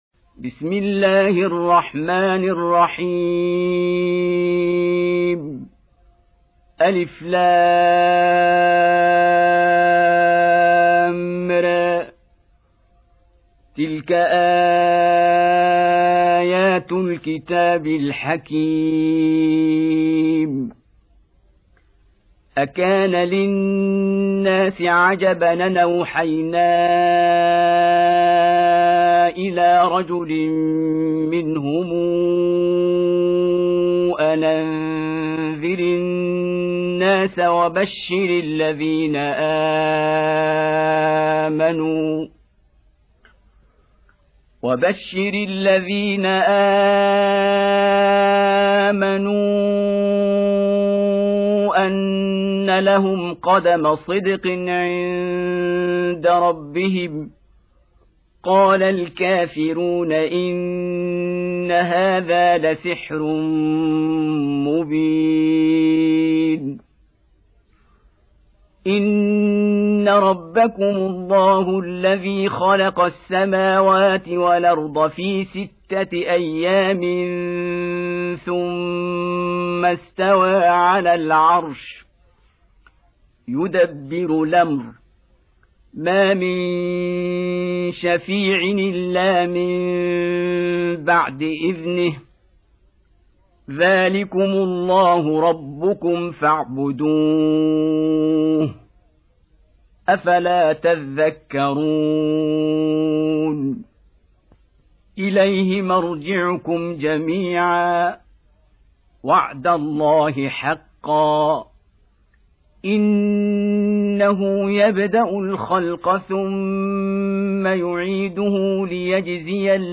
Surah Repeating تكرار السورة Download Surah حمّل السورة Reciting Murattalah Audio for 10. Surah Y�nus سورة يونس N.B *Surah Includes Al-Basmalah Reciters Sequents تتابع التلاوات Reciters Repeats تكرار التلاوات